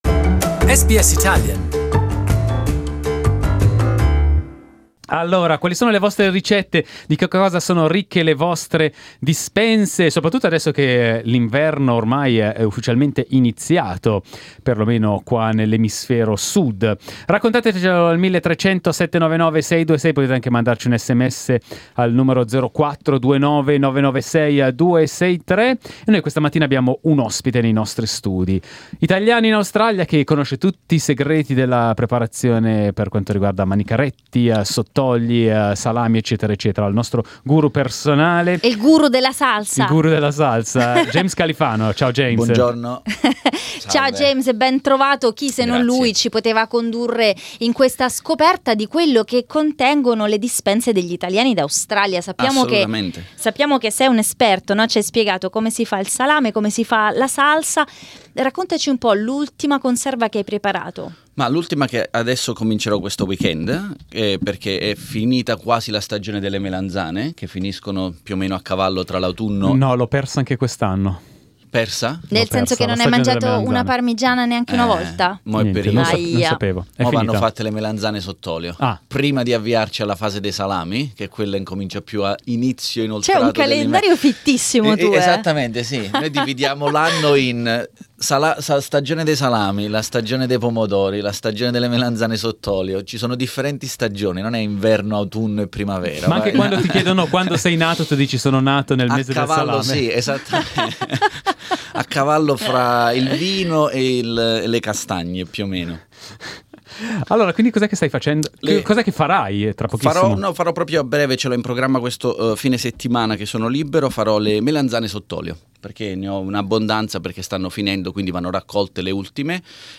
Italians love their home-made products. We have asked two experts to share with us the secrets of their home-made products.